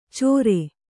♪ cōre